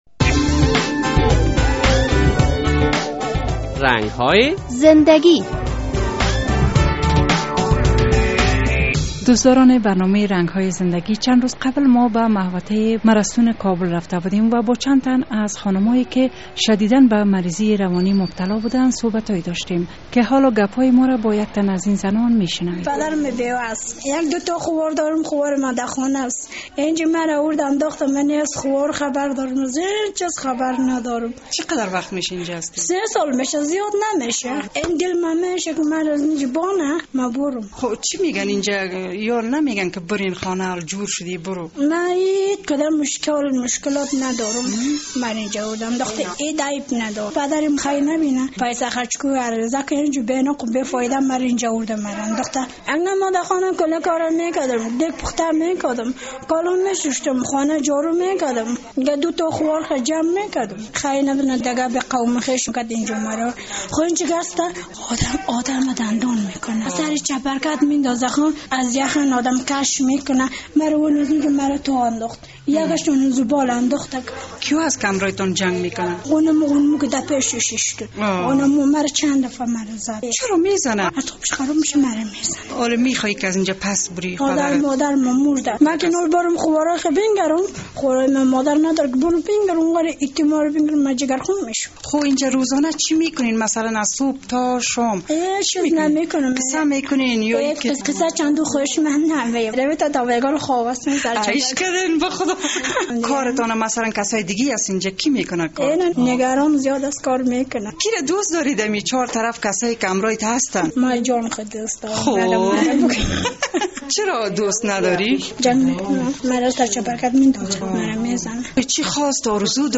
در این برنامهء رنگ های زندگی با یک زنی صحبت شده است که در مرستون کابل تحت تداوی قرار دارد.